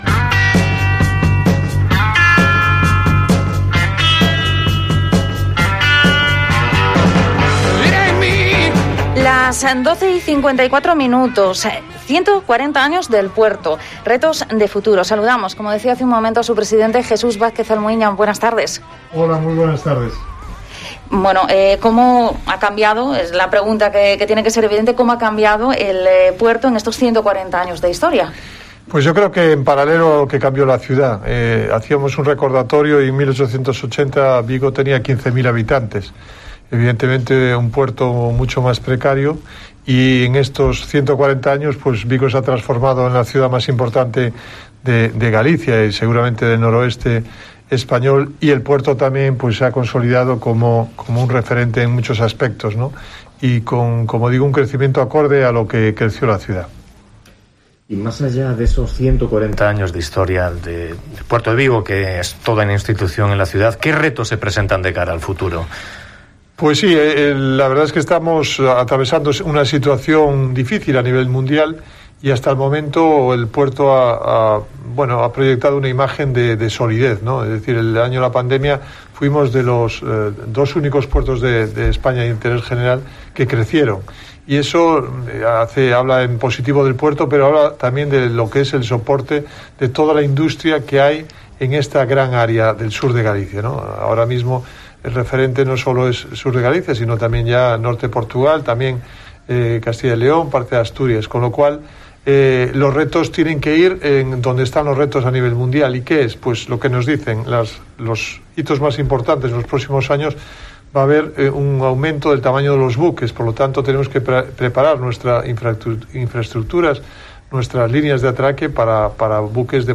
Entrevista a Jesús Vázquez Almuiña, presidente de la Autoridad Portuaria de Vigo